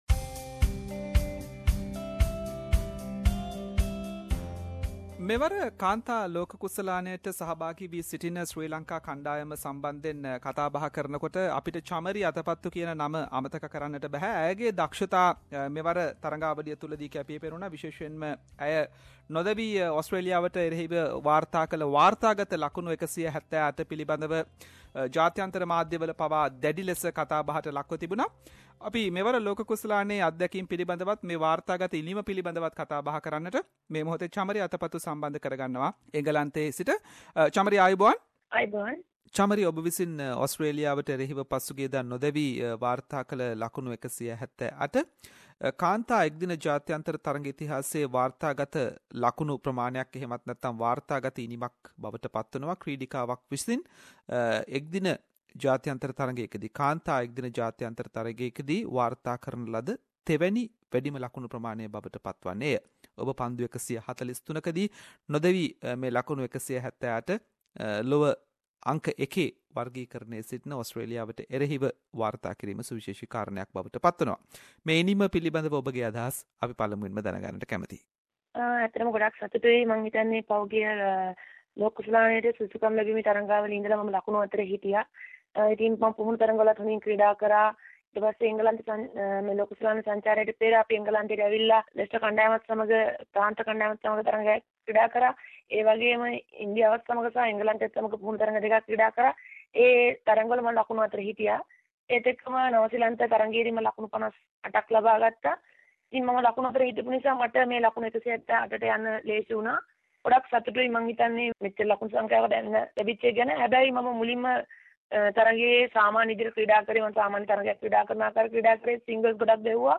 Sri Lankan womens cricket teams prominent member Chamari Athapaththu scored 178 runs not out against Australia in this womens cricket World Cup and this is the third highest womens international score by a player. Chamari joined with SBS Sinhalese to talk about her experince in this World Cup.